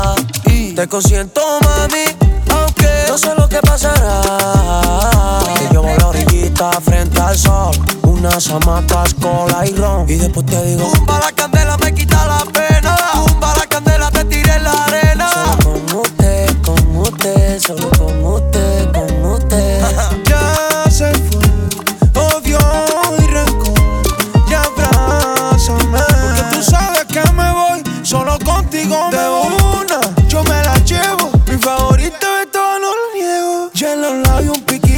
Flamenco Worldwide Hip-Hop Rap
Жанр: Хип-Хоп / Рэп